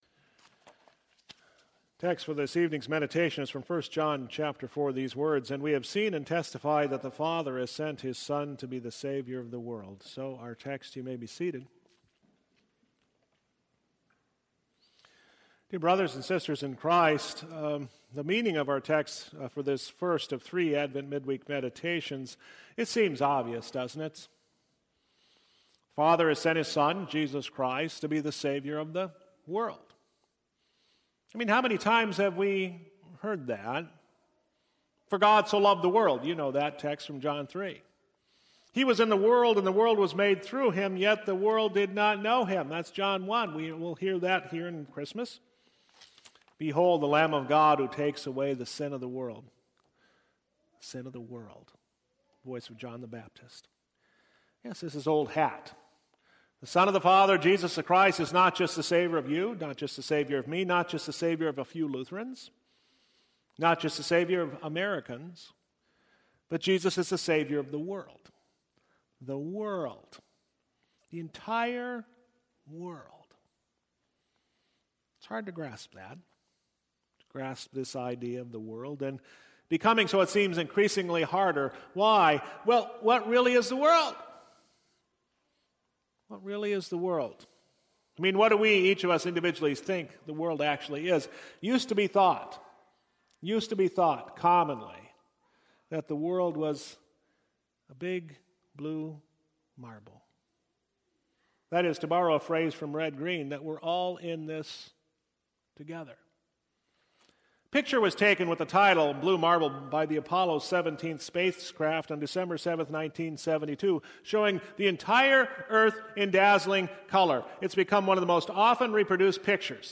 Series: Advent Midweek